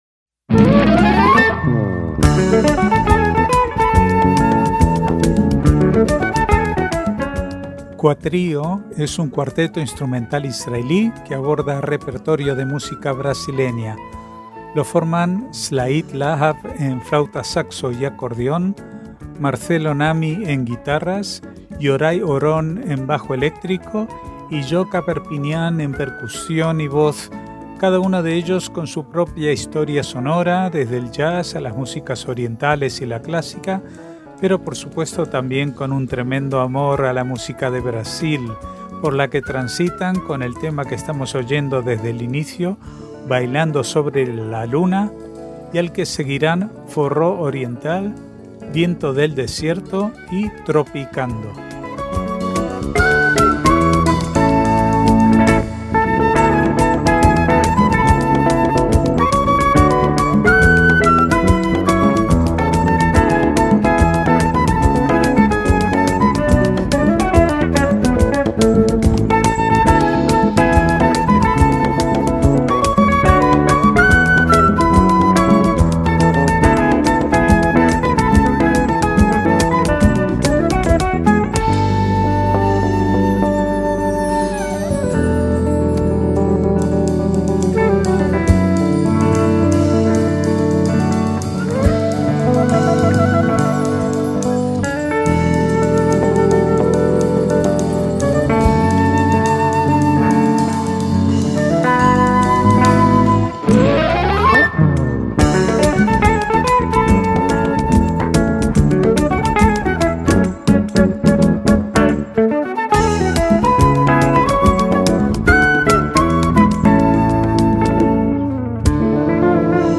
flauta, saxo y acordeón
guitarras
bajo eléctrico
percusión y voz